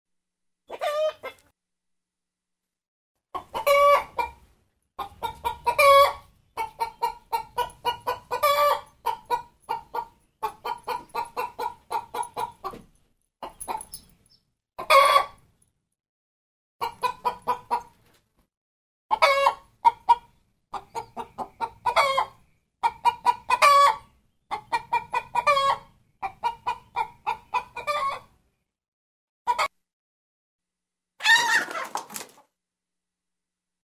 SFX – CHICKEN CLUCKS
SFX-CHICKEN-CLUCKS.mp3